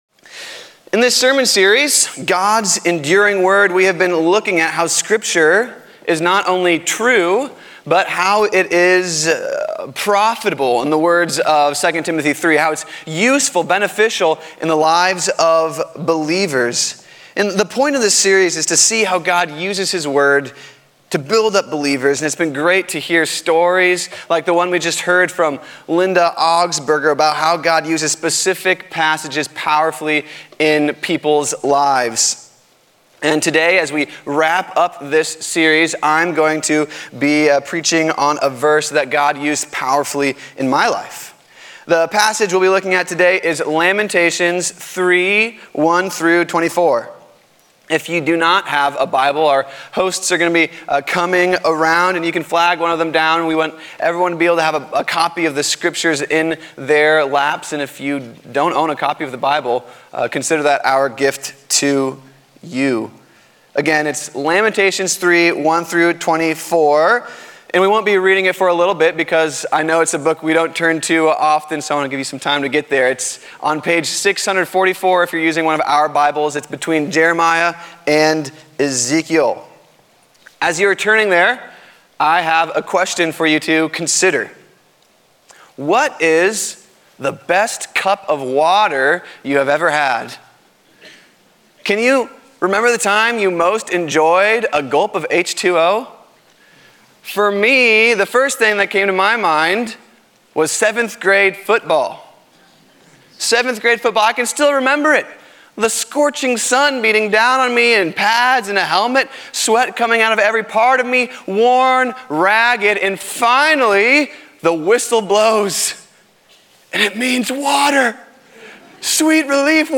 A sermon from the series "God's Enduring Word."